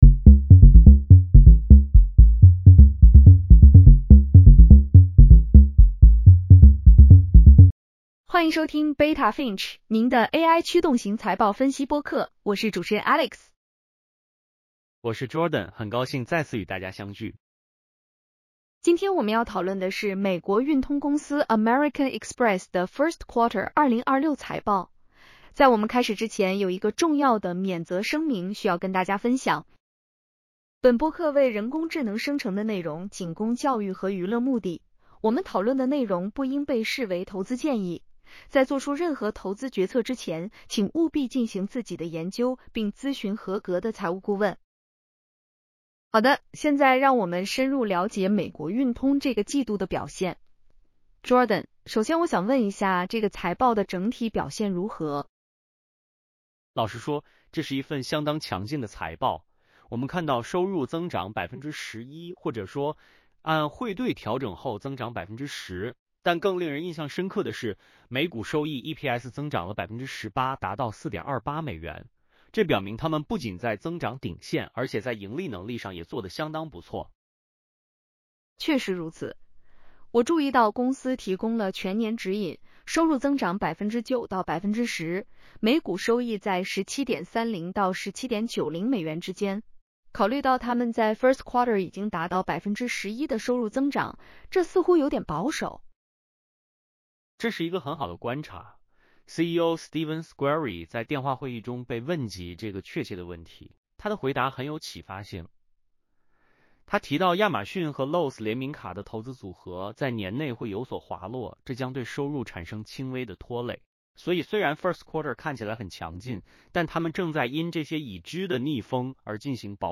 American Express Q1 2026 earnings call breakdown.